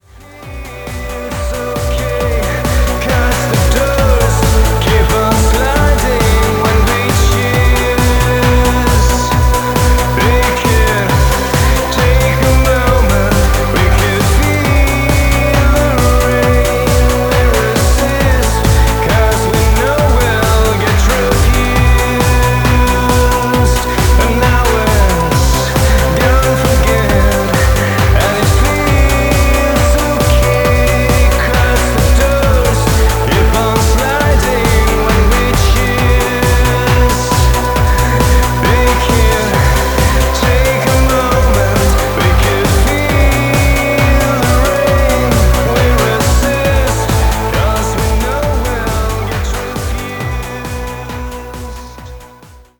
EBM, Electro-Pop, Synthpop
from EBM to synthpop and gothic influences.